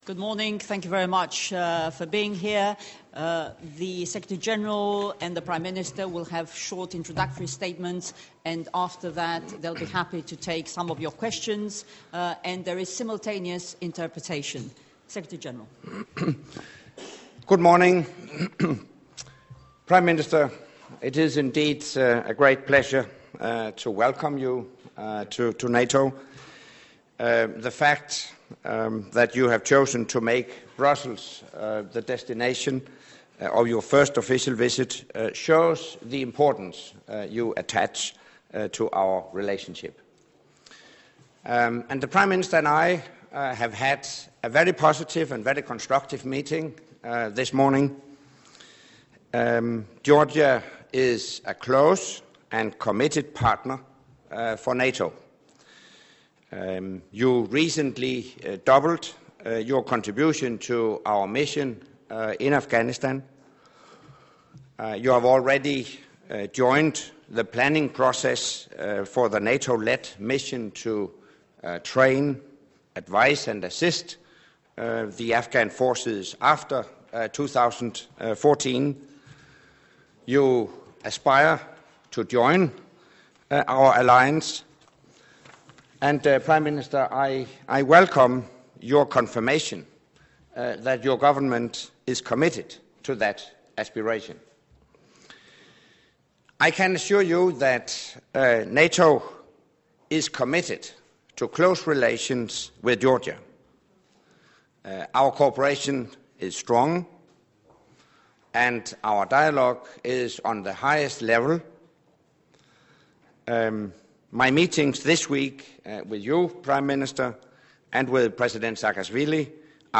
After meeting with NATO Secretary General Anders Fogh Rasmussen, they jointly talked to the press.
Joint press point with NATO Secretary General Anders Fogh Rasmussen and the Prime Minister of Georgia, Bidzina Ivanishvili